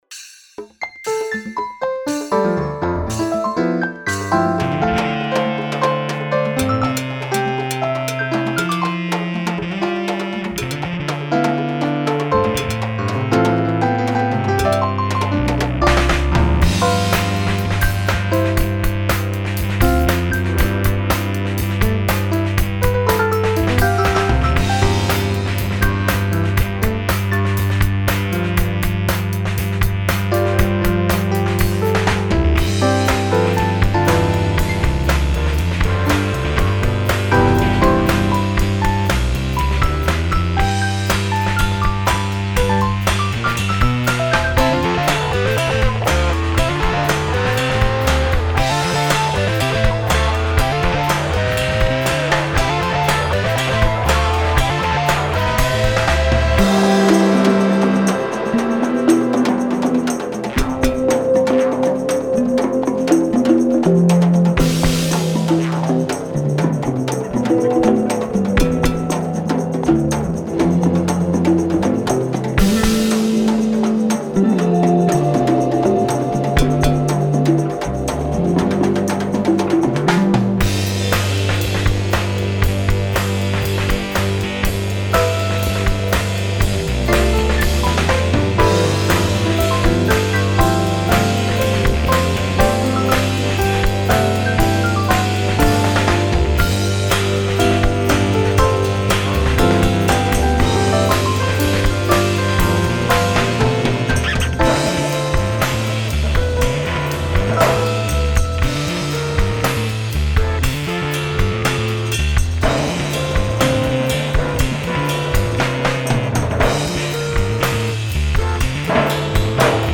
saxophones.
album recorded digitally.